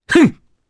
Roi-Vox_Attack3_jp.wav